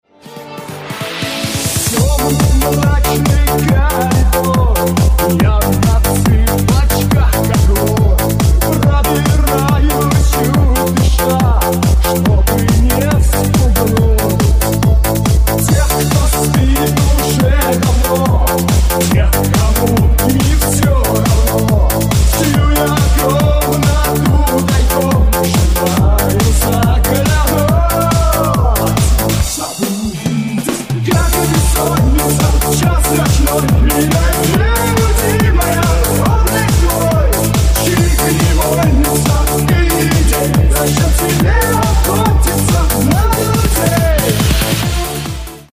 • Качество: 128, Stereo
Eurodance
dance remix в стиле 90-х